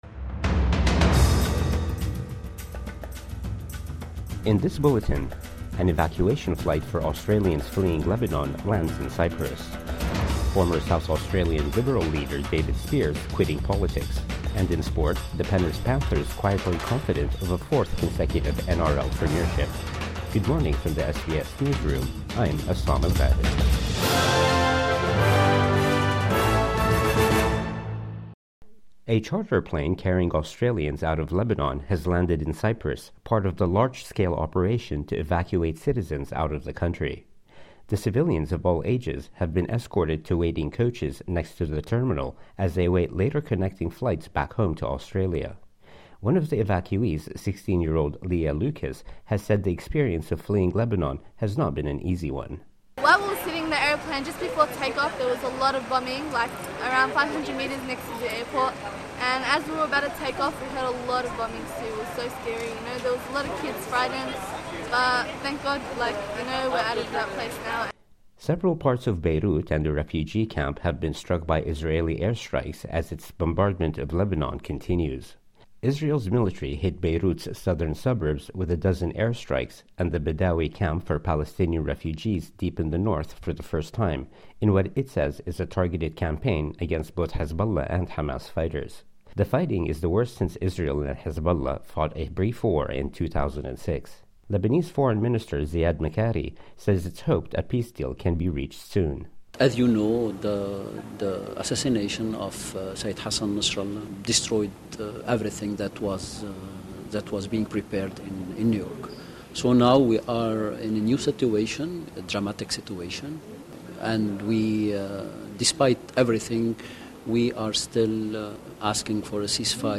Morning News Bulletin 6 October 2024